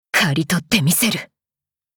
文件:Cv-40503 battlewarcry ex1100.mp3 - 萌娘共享
贡献 ） 协议：Copyright，人物： 碧蓝航线:乌尔里希·冯·胡滕语音 您不可以覆盖此文件。